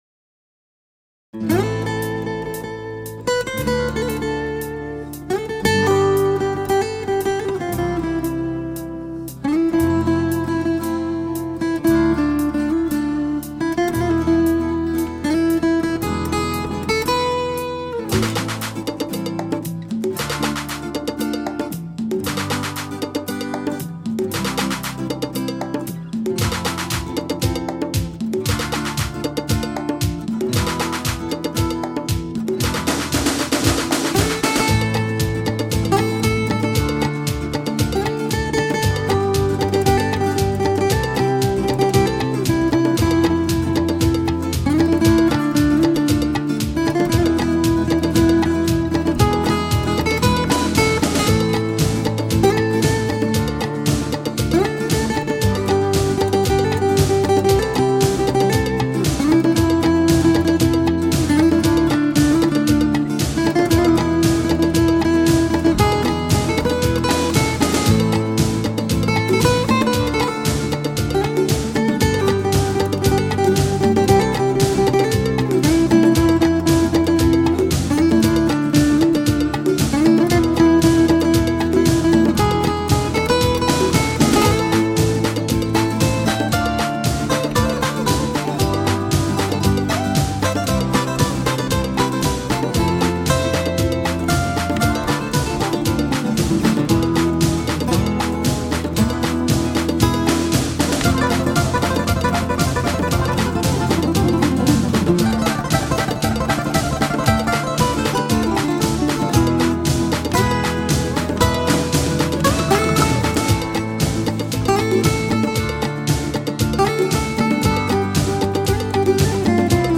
Latin Guitar
Acoustic Guitar